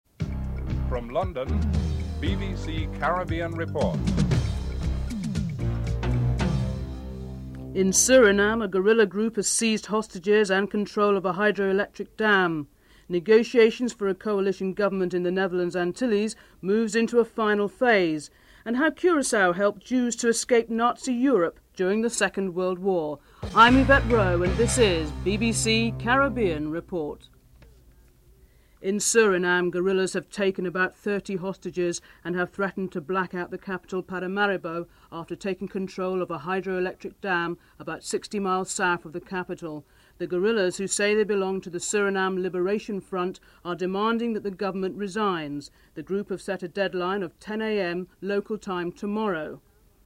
9. Theme music (14:45-15:05)